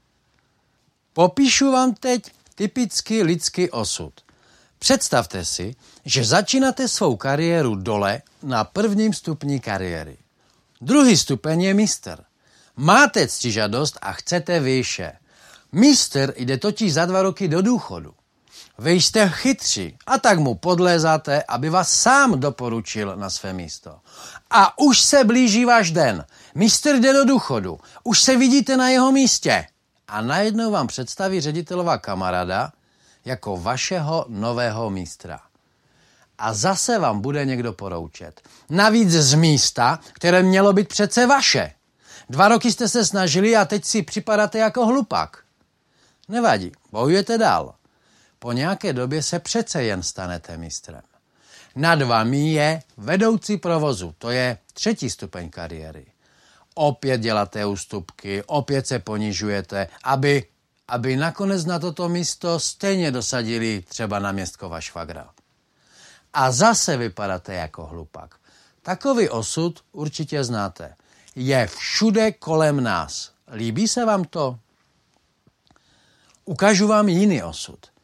Ukázka z knihy
multi-level-marketing-strasak-nebo-prilezitost-audiokniha